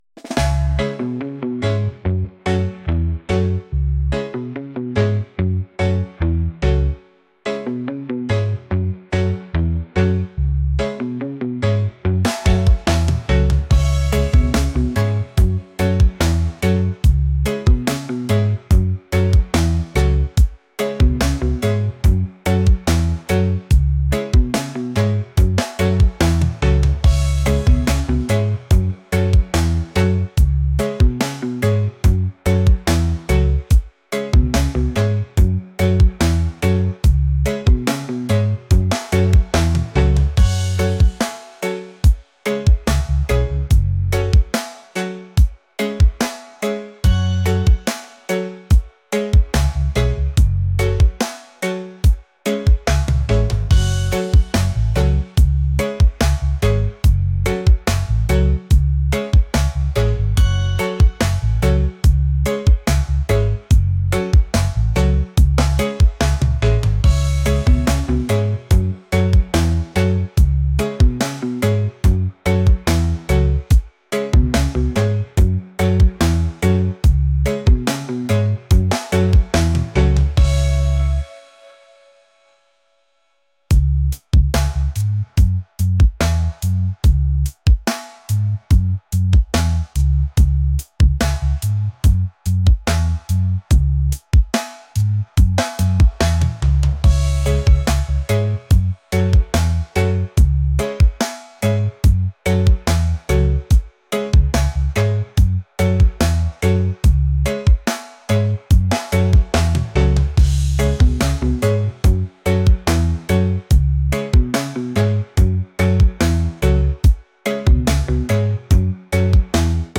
reggae | funk | soul & rnb